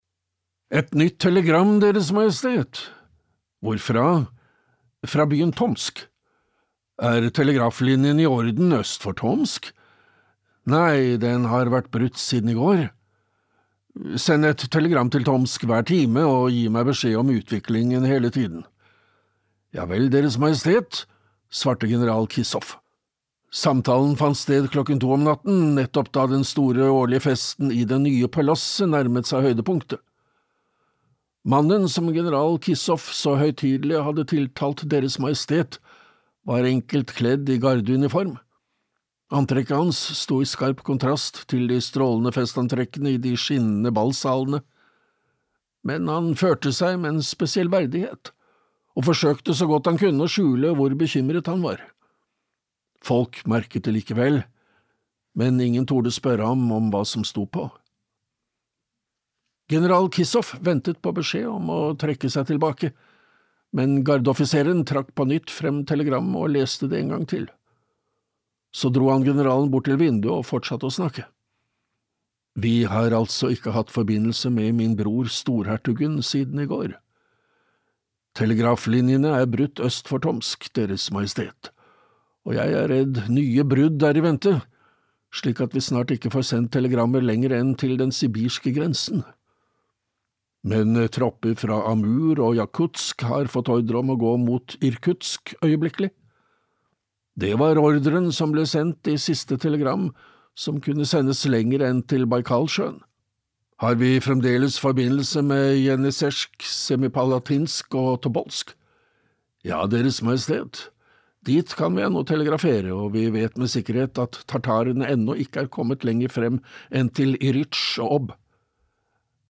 Tsarens kurér (lydbok) av Jules Verne